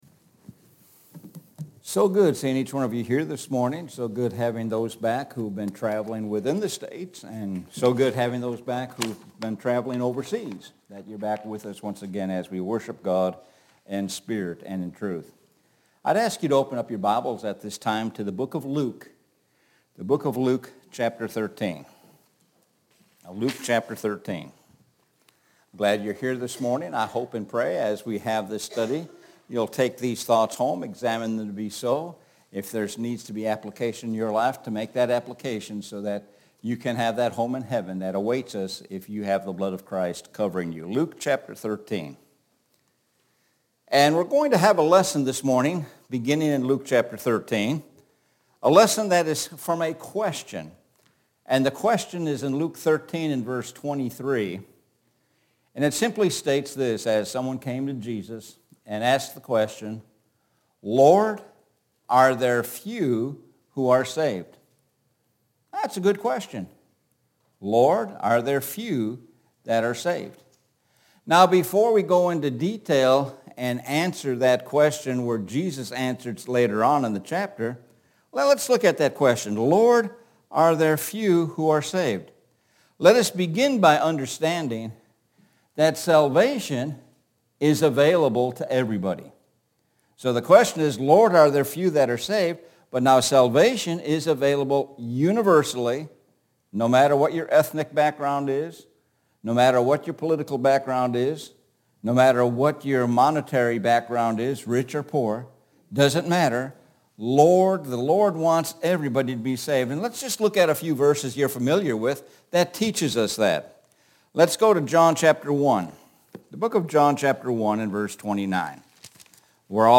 Sun Am Sermon Lessons from a Question – 04 June 2023